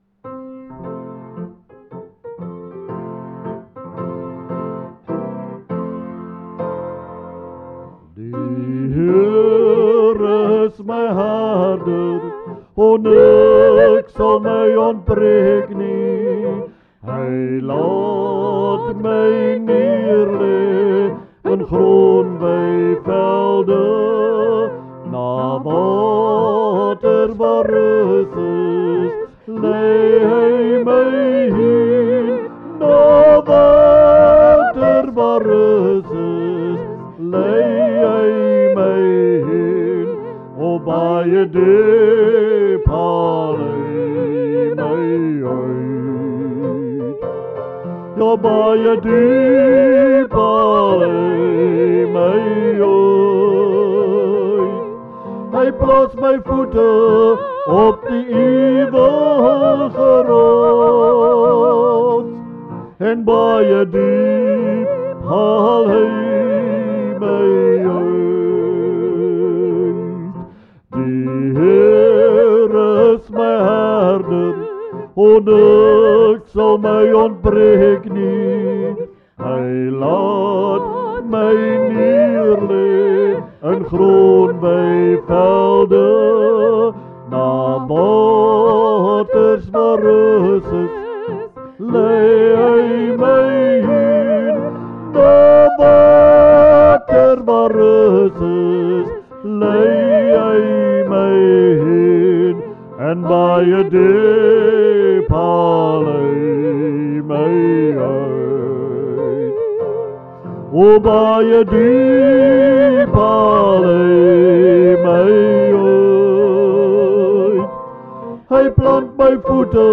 Liedere - Begeleiding en samesang
Dit is geensins professionele opnames nie.